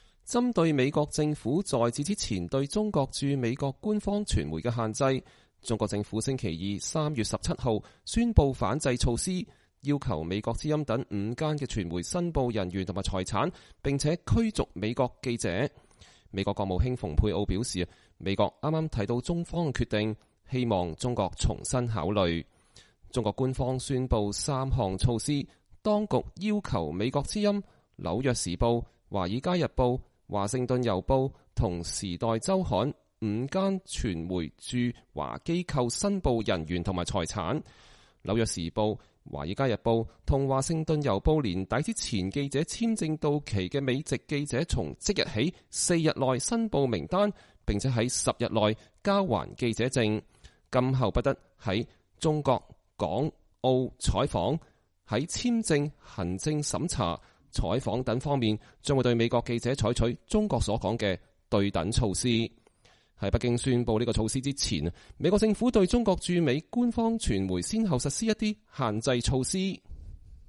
星期二，在美國國務院的記者會上，蓬佩奧被問到了中國政府採取的最新行動。